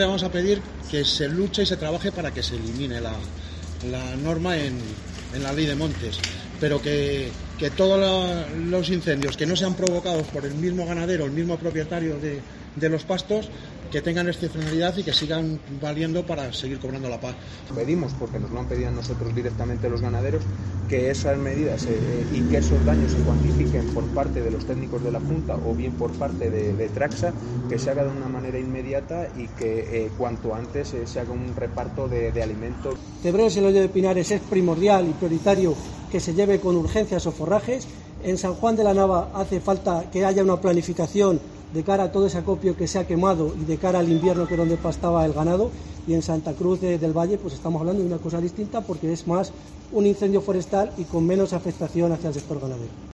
Carrusel de voces representantes ganaderos